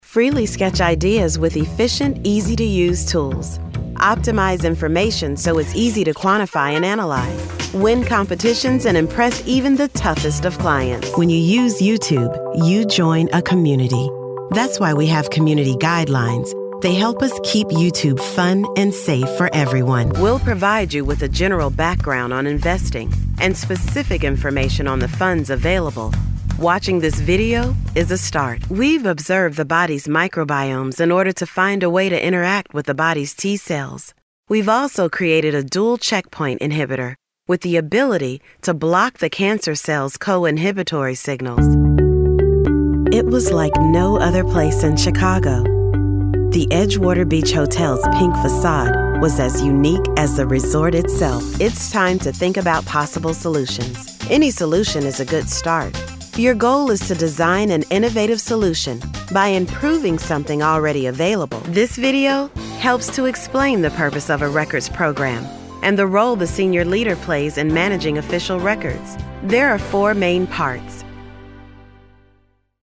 Has Own Studio
e-learning